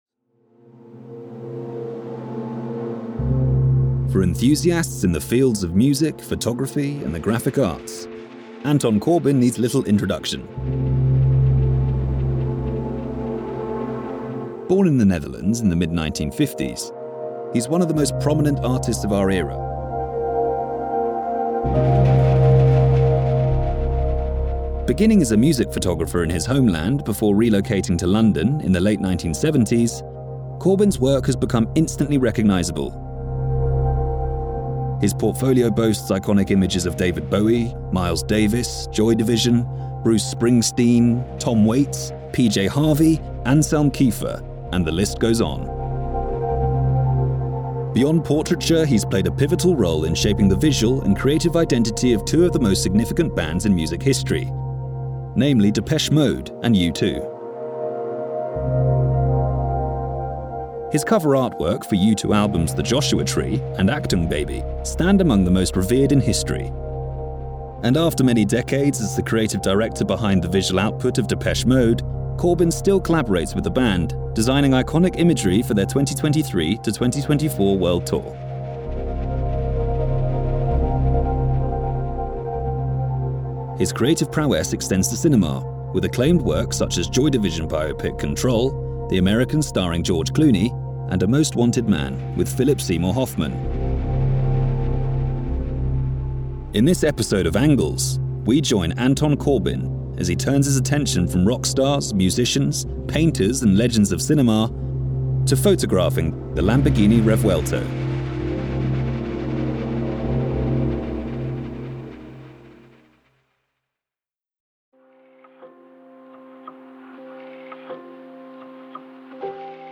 BBC English (RP), London, Cockney, Estury, Southern Irish, General American, Australian, South African.
Young Adult
His voice breathes warm life into Corporate Voice-Overs, Documentaries, E-Learning projects, Commercials, Animations & Cartoons, Video Games, Audiobooks and much more.
Podcast Demo - from Lamborghini's official Pocast (Spotify 2024) .mp3